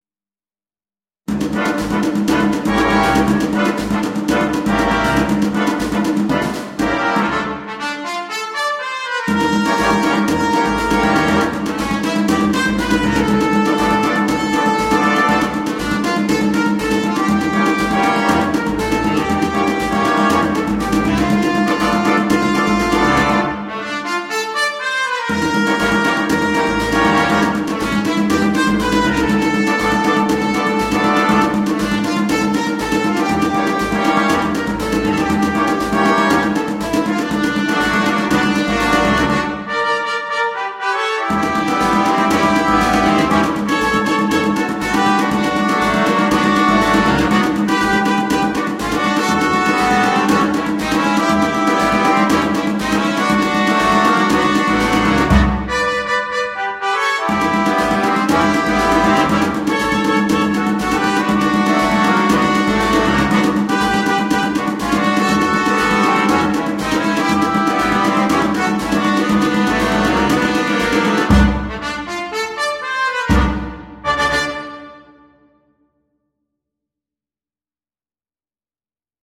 для джаз-бэнда.